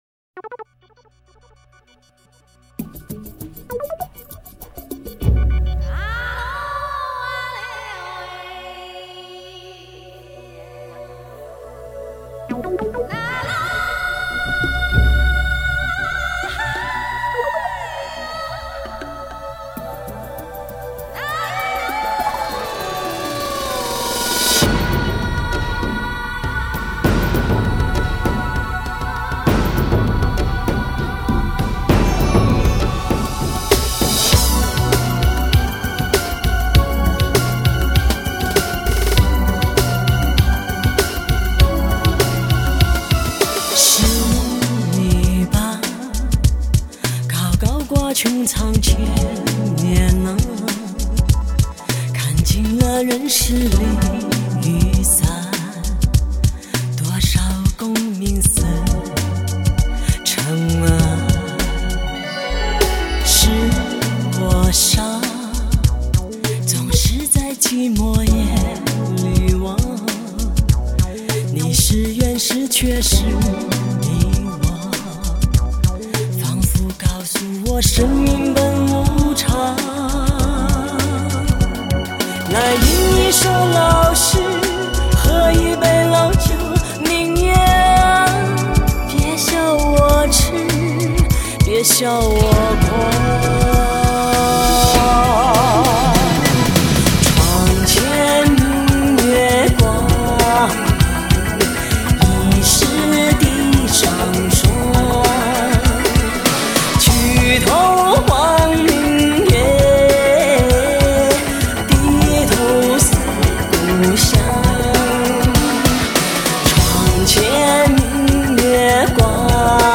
世纪末世界音乐大碟